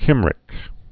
(kĭmrĭk, sĭm-)